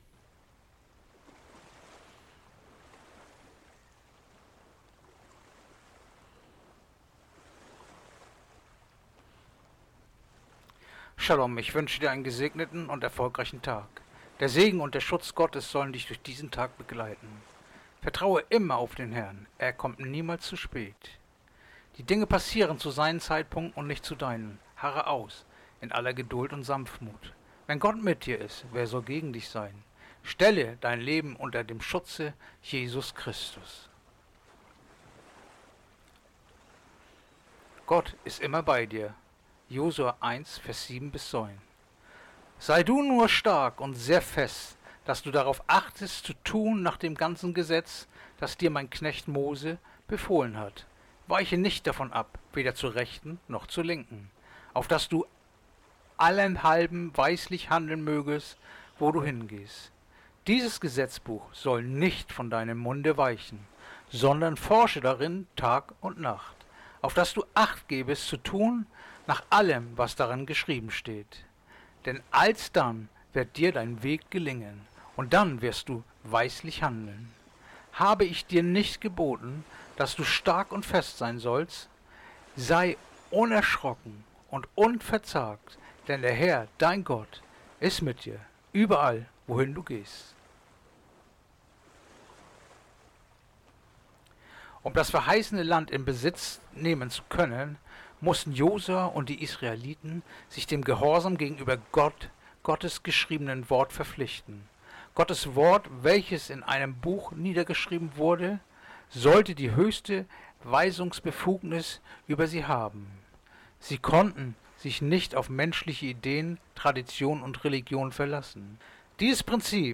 Andacht-vom-15-Juli-Josua-1-7-9